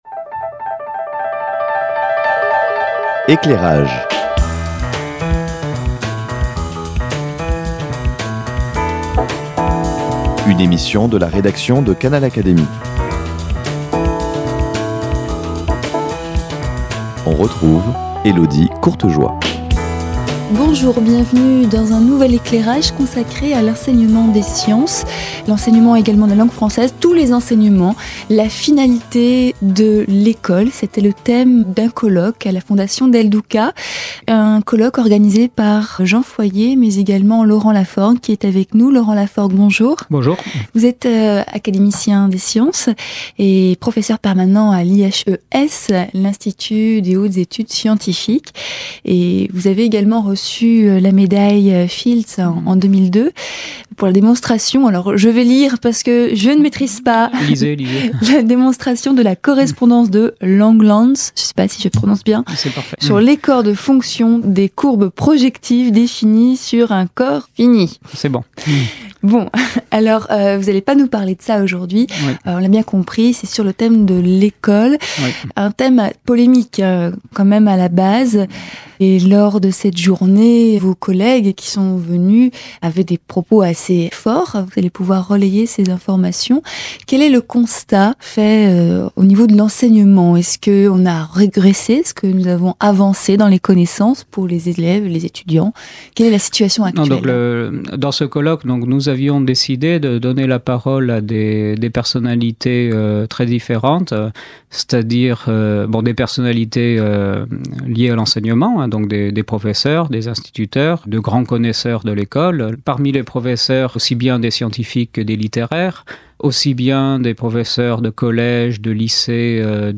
Avec son franc-parler, Laurent Lafforgue nous livre son point de vue.